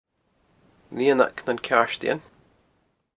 Gaelic name: Lianag nan Ceàirdidhean Name in Original Source: Lianag nan Ceàirdidhean English meaning: Little meadow of the tinkers Placename feature: Field Notes: In local Applecross dialect, this name is pronounced Lee-un-ak nan Cyeearsht-in-en.